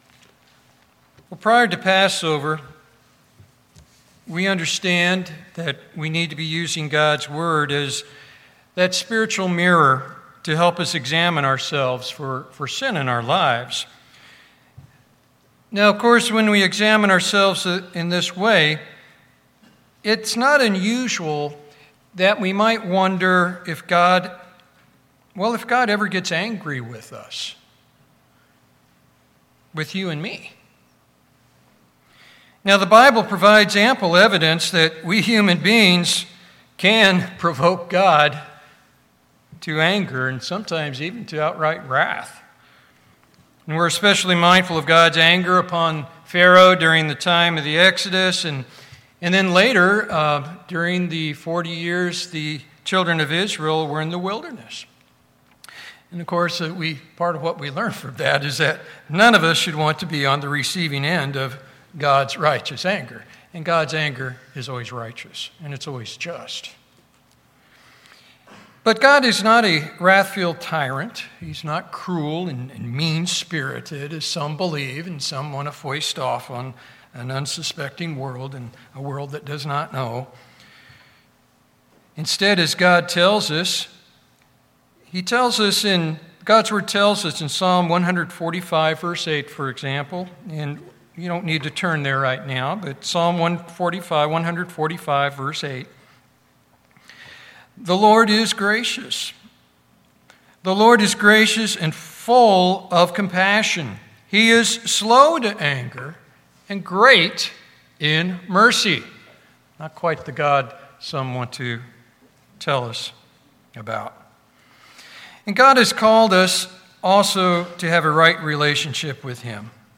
So, in today’s sermon, we’ll consider several things that ancient Israel did that provoked God to anger so that we ourselves might be warned and avoid doing them, too. We’ll also be reminded and encouraged about how longsuffering and merciful God is and always has been. Finally, we’ll consider the only option we can take to have a right and pleasing relationship with God.